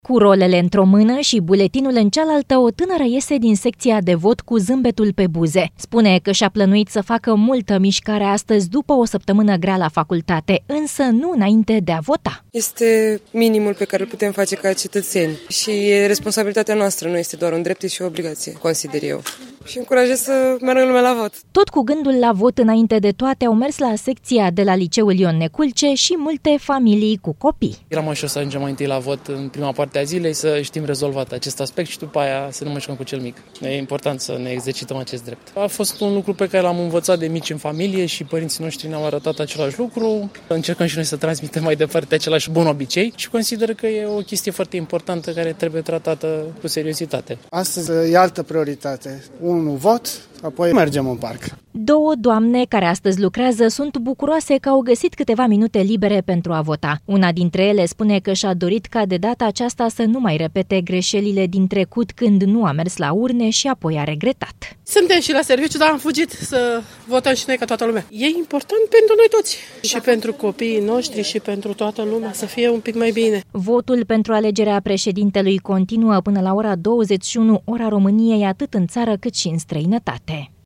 Bucureștenii care locuiesc în apropierea Primăriei sectorului 1 merg la vot la Liceul „Ion Neculce”.
„Este minimum pe care îl putem face ca cetățeni și e responsabilitatea noastră. Nu este doar un drept, e și o obligație, consider eu”, a spus tănâra.
„Astăzi e altă prioritate. Un vot, apoi mergem în parc”, a declarat un alt bărbat.
„Suntem și la serviciu, dar am fugit să votăm și noi ca toată lumea. E important pentru noi toți. Și pentru copiii noștri, și pentru toată lumea să fie un pic mai bine”, a declarat o femeie.